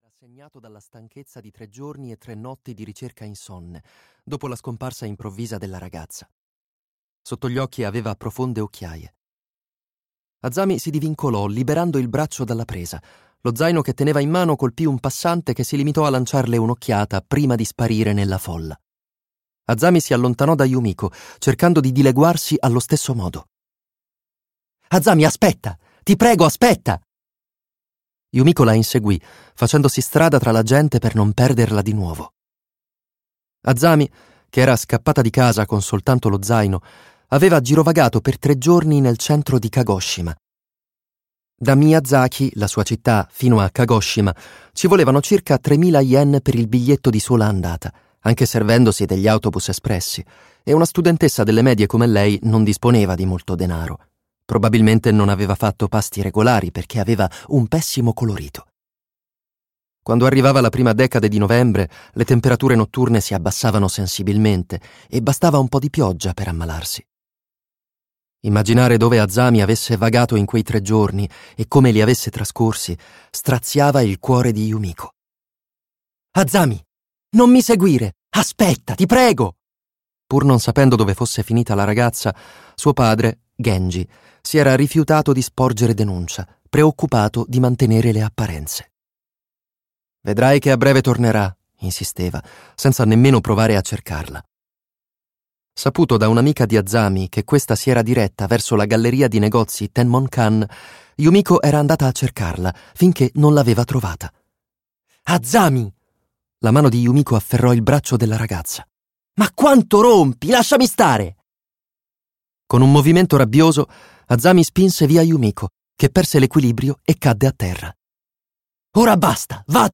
"La bottega del tempo ritrovato" di Toshikazu Kawaguchi - Audiolibro digitale - AUDIOLIBRI LIQUIDI - Il Libraio